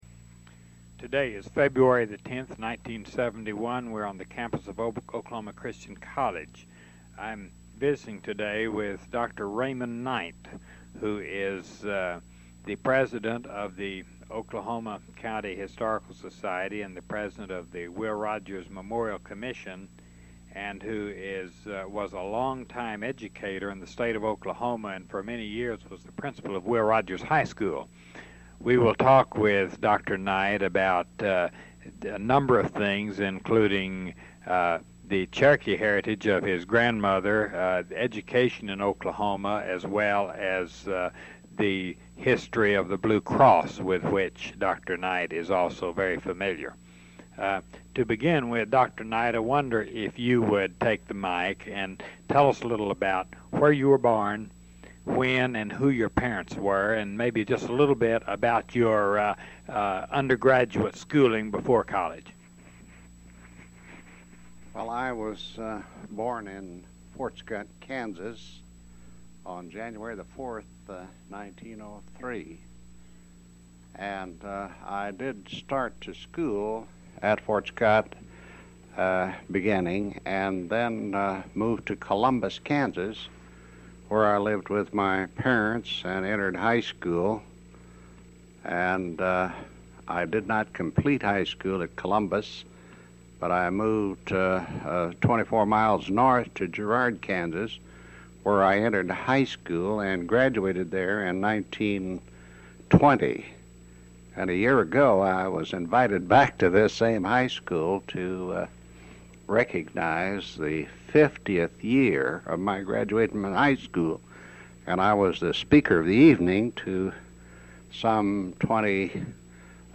oral history interviews